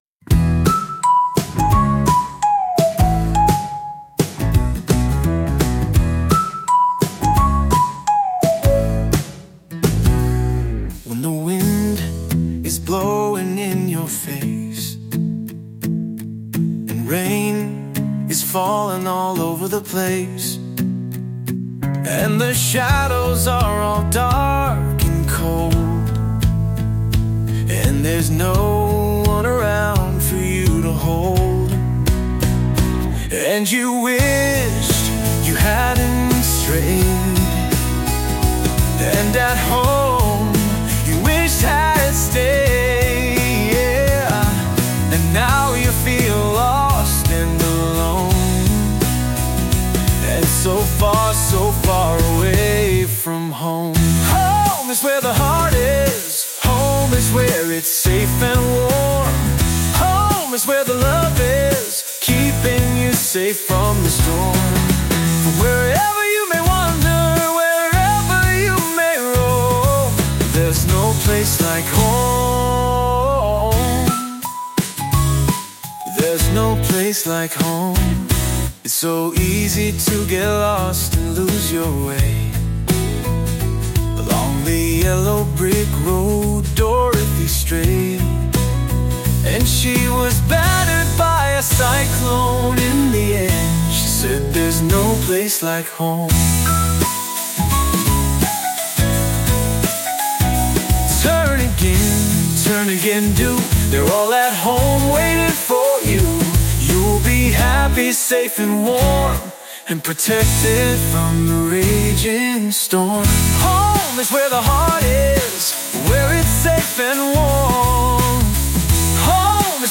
a heartfelt and cozy children’s song